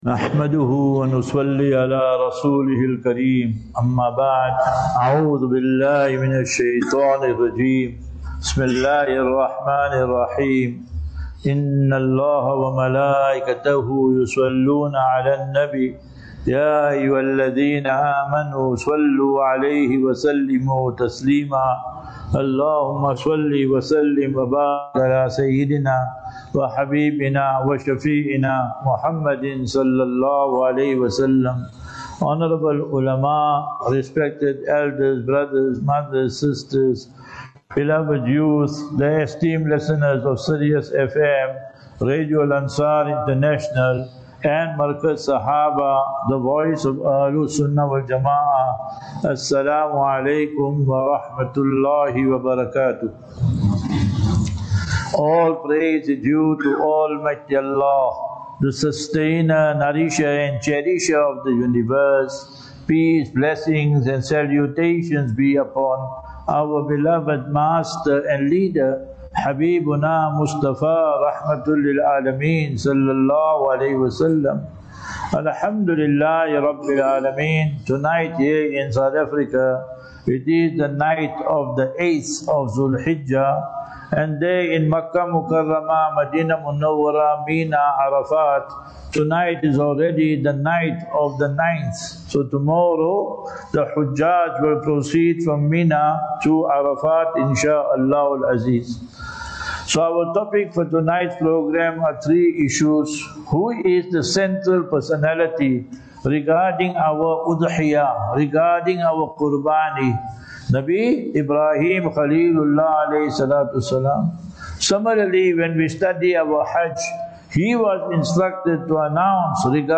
Masjidus Salaam Sunford, Phoenix
Lectures